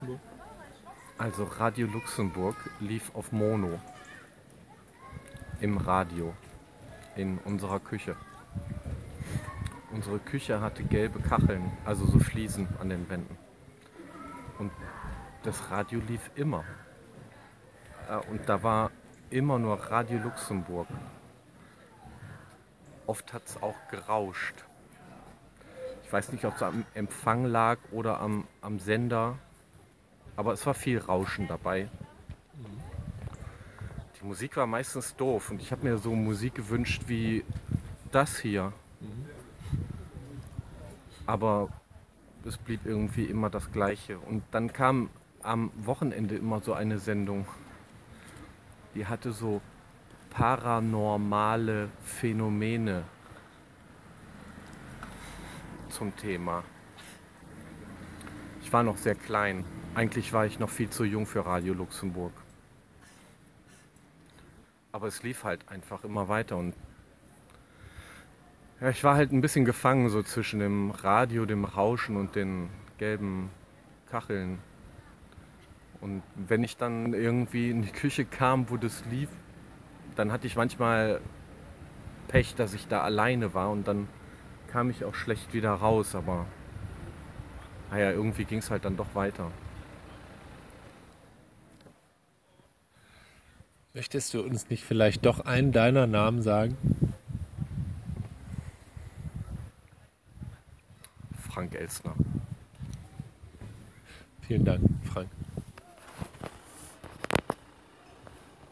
Geschlecht: männlich